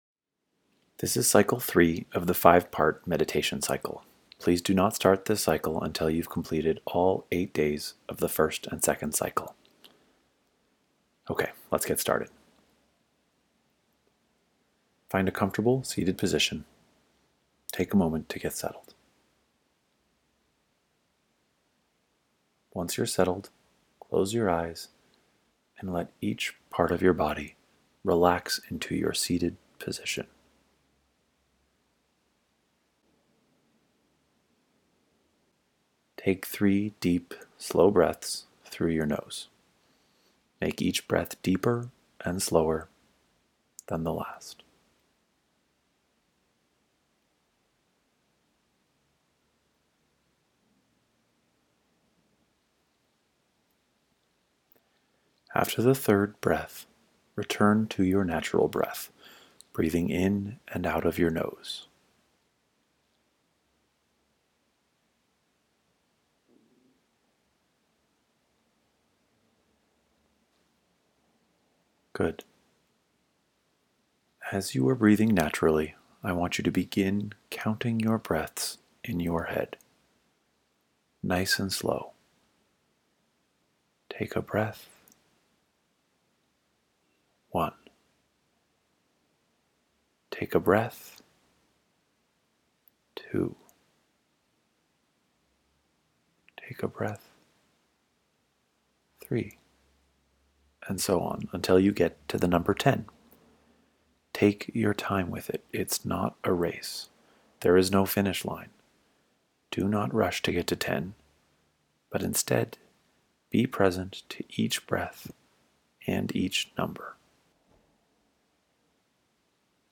I have broken down a standard breathing meditation into 5 separate steps (or “cycles”), starting with the simplest and building in complexity.
You provide gentle, human support as I listen.